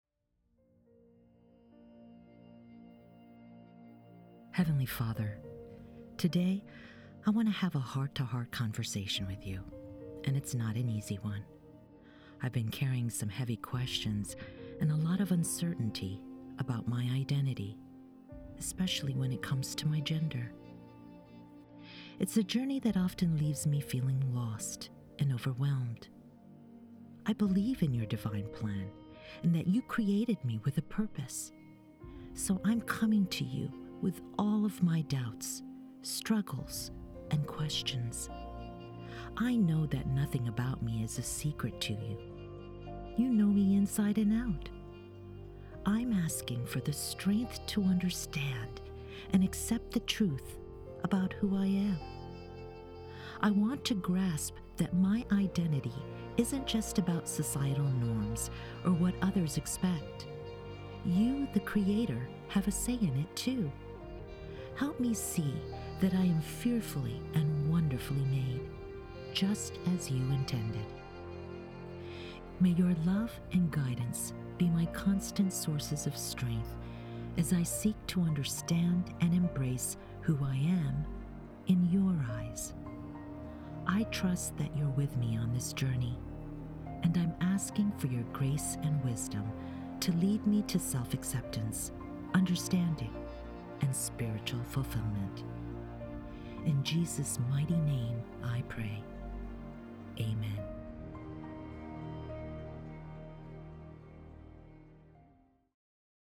PRAYER
As you navigate the intricate journey of discovering your identity, especially in terms of gender, let this audio prayer become a deeply personal conversation between you and God offering solace, understanding, and a sanctuary for embracing your true self with love and acceptance. You are not alone on this path, and may these whispered words bring you comfort and guidance.